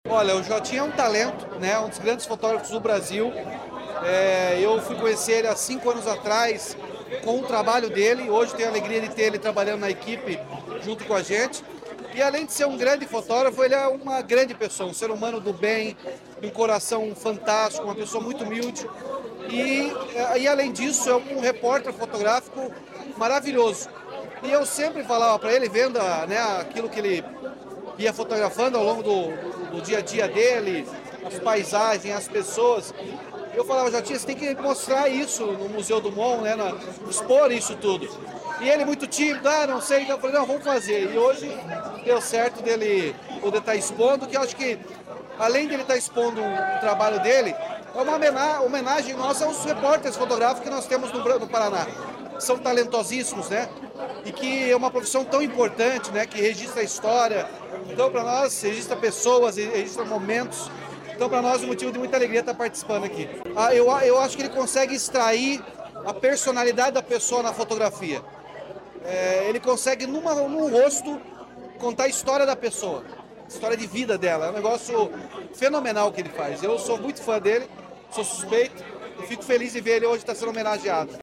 Sonora do governador Ratinho Junior sobre a abertura da mostra fotográfica “Instante do Olhar”, no MON